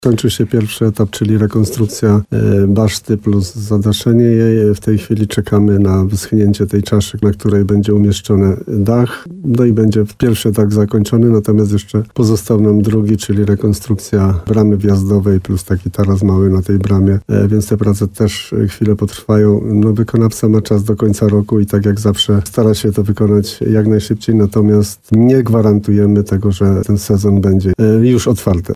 Jak mówił w programie Słowo za Słowo w radiu RDN Nowy Sącz wójt gminy Rytro Jan Kotarba, są starania, by ponownie udostępnić zamek jesienią.